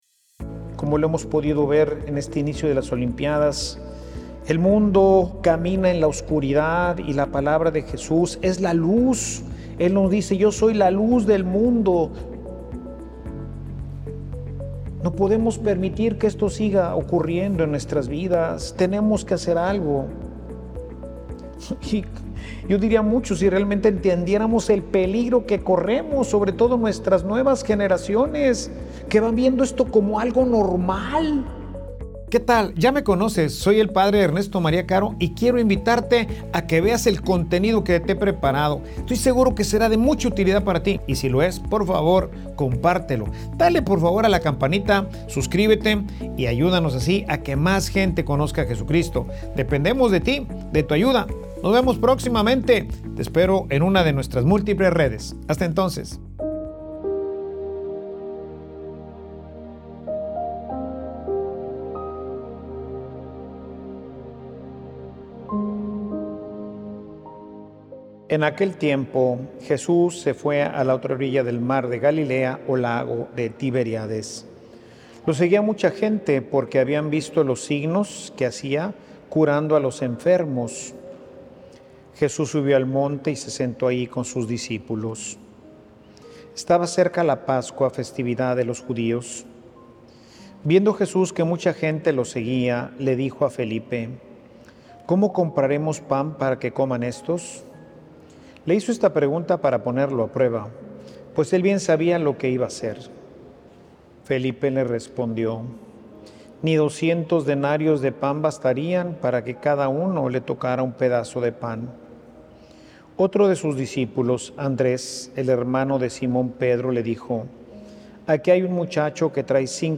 Homilia_Aqui_esta_mi_vida_senor.mp3